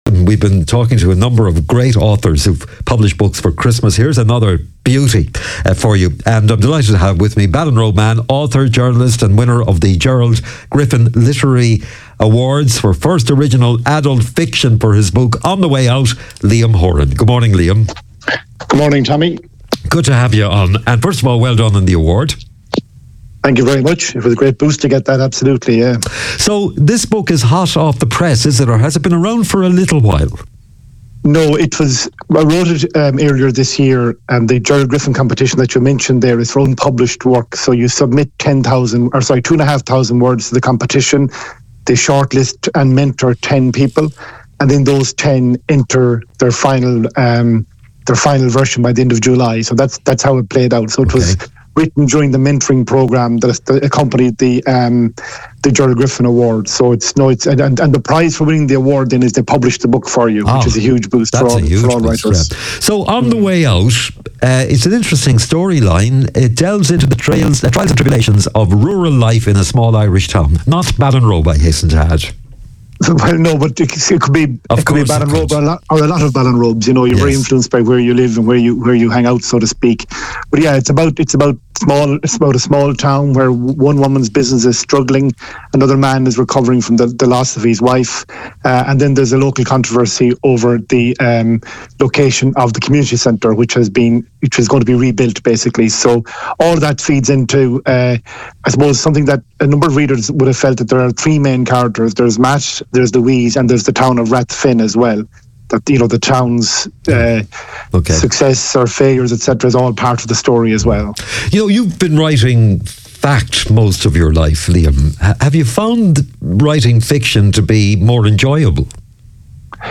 It was a warm, lively conversation, and if you’d like to hear it back, you can listen here: